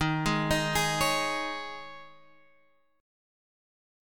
D#7sus4 chord